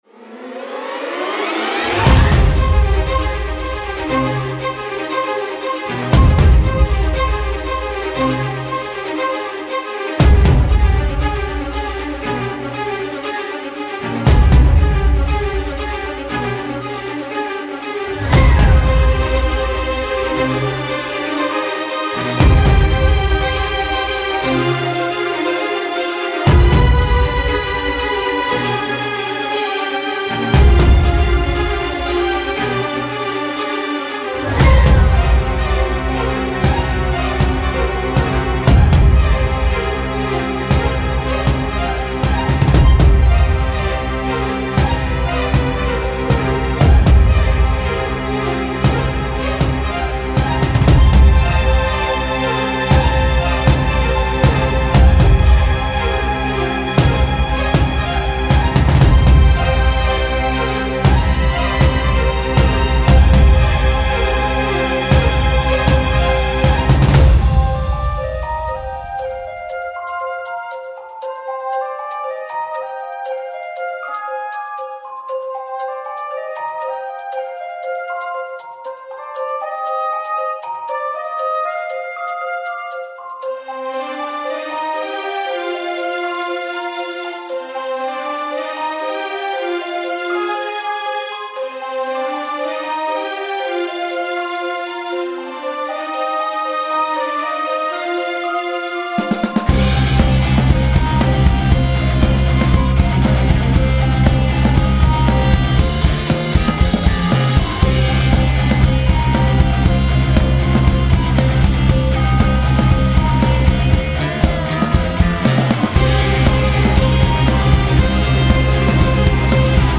Battle Orchestra Music
Genre: Epic Orchestra Feel free to use my music, it would be great if you credit me.